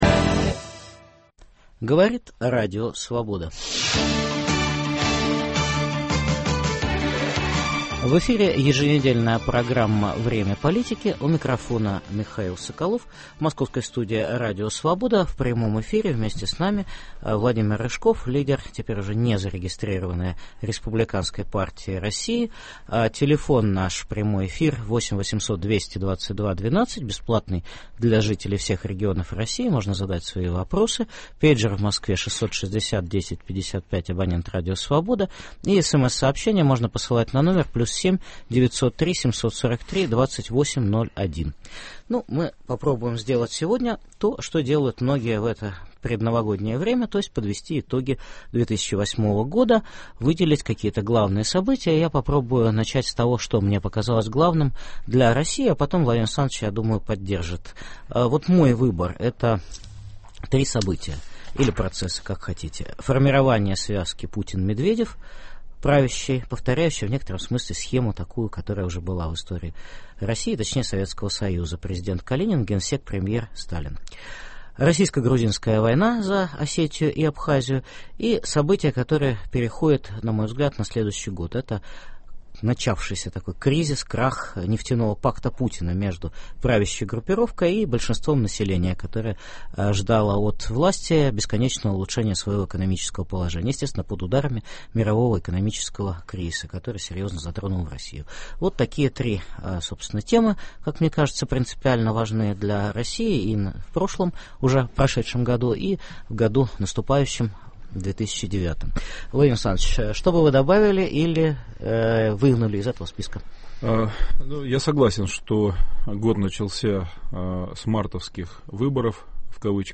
Политические итоги 2008 года в прямом эфире обсуждаем с лидером республиканской партии России Владимиром Рыжковым.